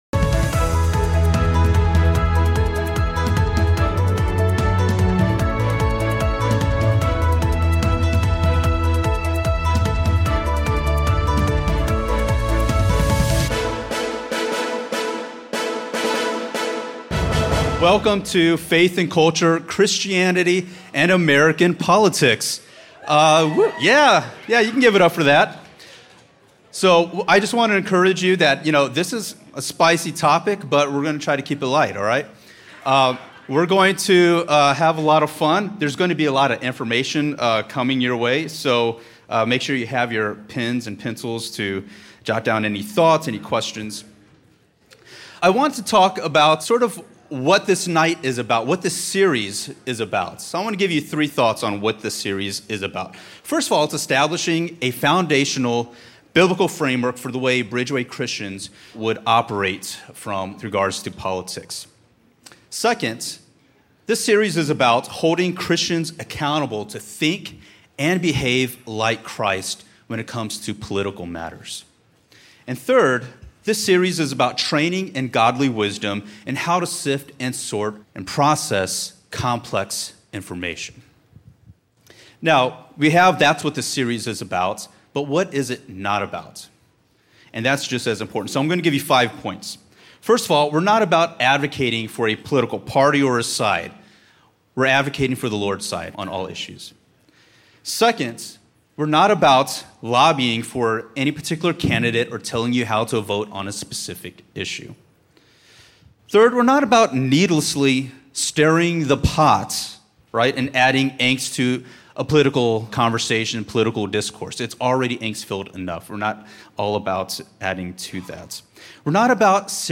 along with a short Q & A. In this session, our presenters look at some of the problems with how Christians are engaging with politics today, and how our political world came to be what it is.